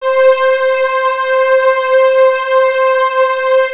Strings (5).wav